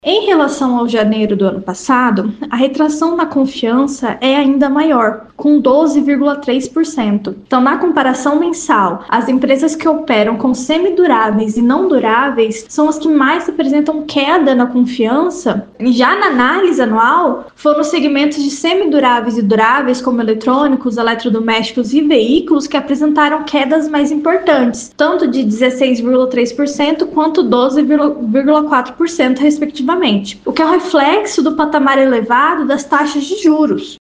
Em entrevista ao programa da FM Educativa MS 104.7 “Agora 104”